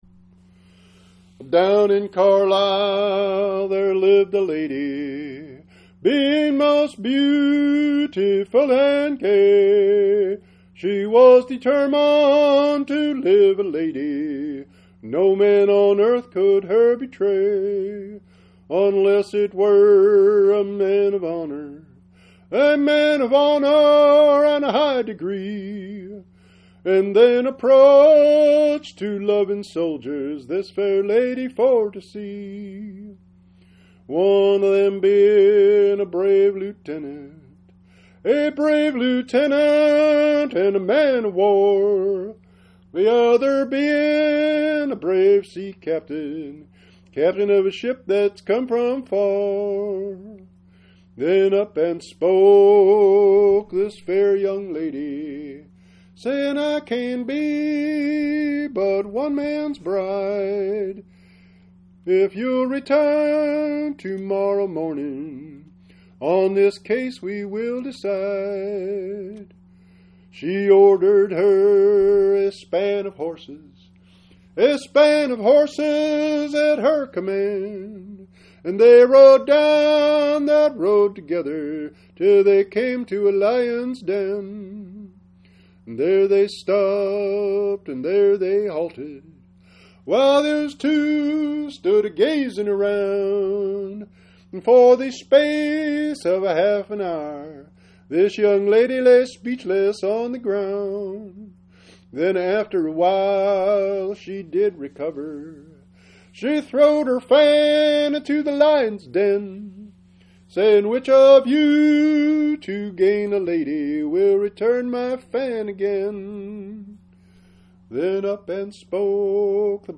PDF Files: ballad